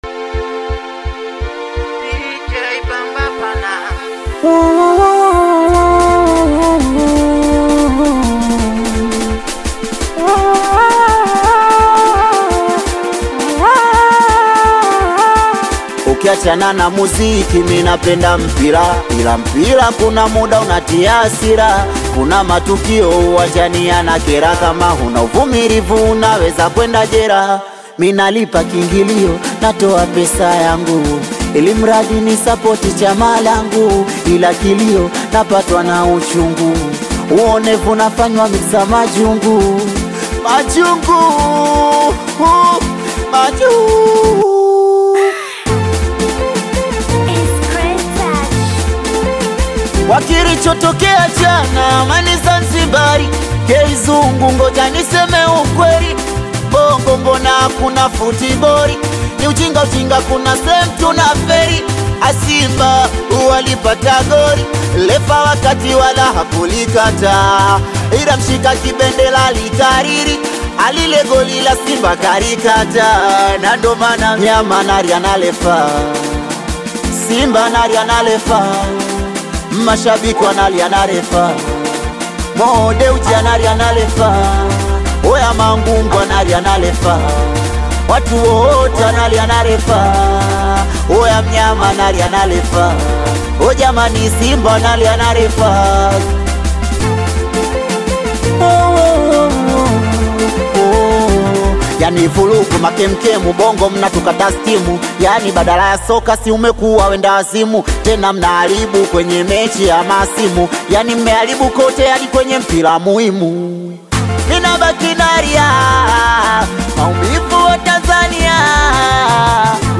Tanzanian Singeli track
street-style rap delivery
energetic beats